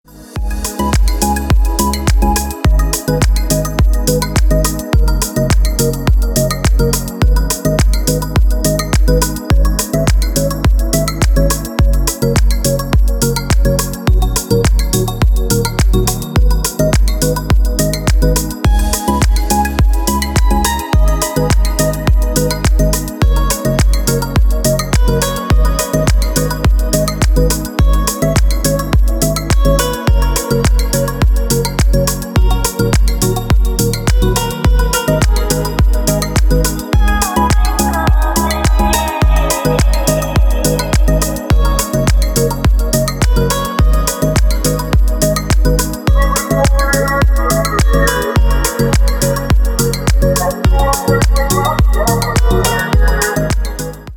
• Песня: Рингтон, нарезка
Спокойные рингтоны